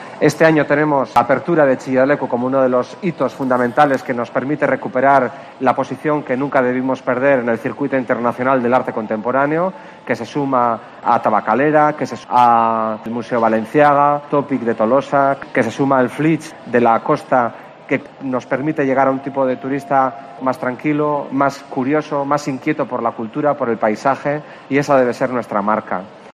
Denis Itxaso, diputado de cultura.